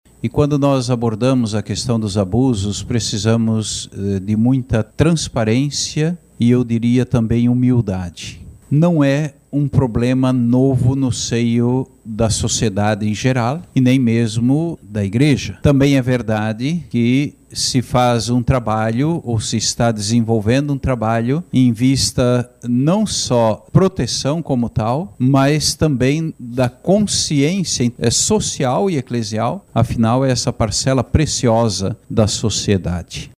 Em Aparecida, a CNBB dedicou uma das coletivas de imprensa ao debate sobre uma ferida para a Igreja e para a sociedade: os abusos contra menores e pessoas vulneráveis.
O presidente da CNBB, Dom Jaime Spengler, abriu a coletiva e reconheceu que o tema exige seriedade da Igreja e da sociedade.